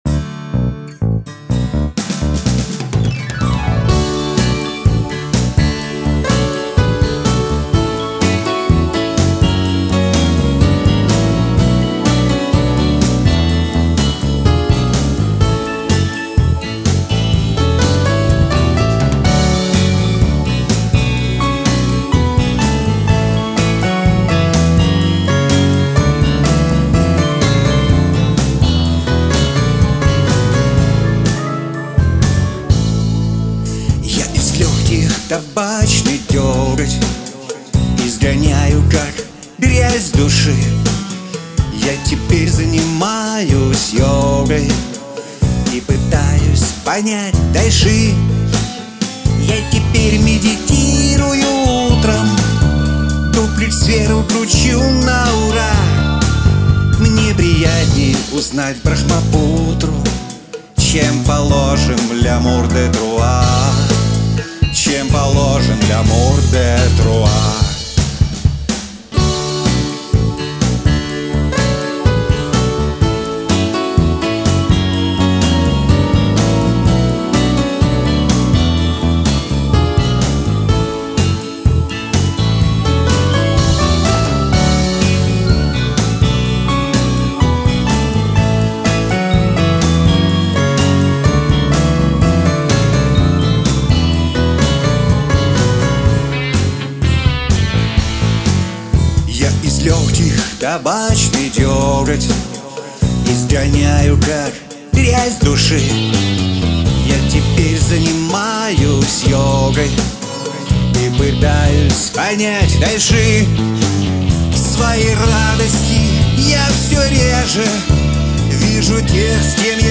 Все инструменты - VST,кроме гитары.Её решили писать прямо в пульт.
Качество,конечно,не ахти...( Но уже есть, с чем идти в Студию.